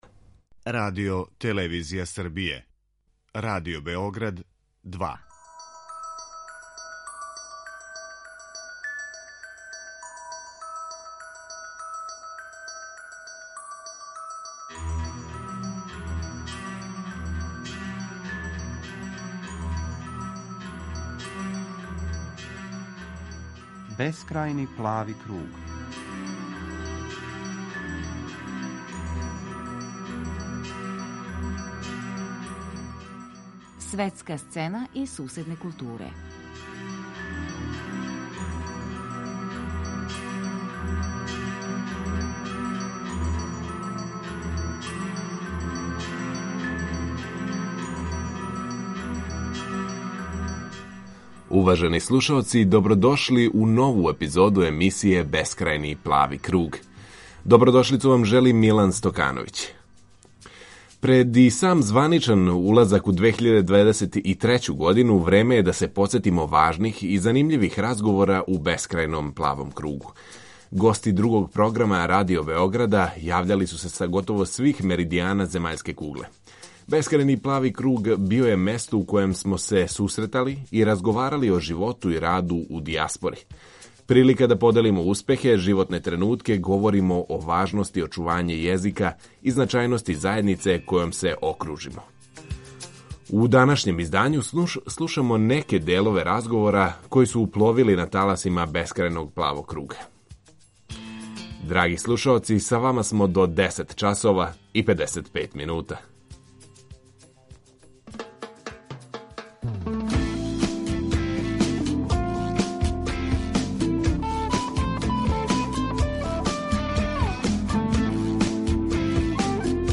У данашњем издању слушамо неке делове разговора који су пловили на таласима Бескрајног плавог круга .